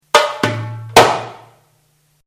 Rythmes & Sons, distributor and manufacturer of symphonic percussion instruments offers the Darbouka Ø7' smooth aluminium body
Darbouka Ø7"/17